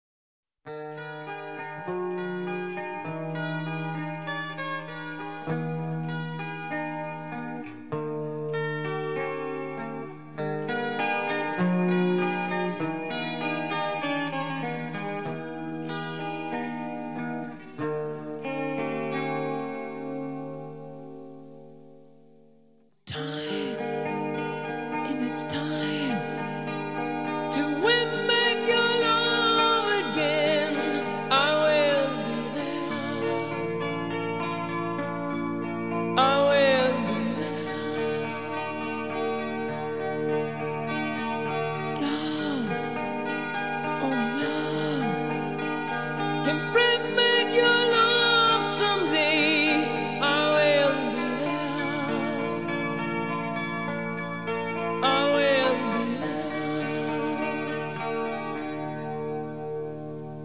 Рок
Самые известные баллады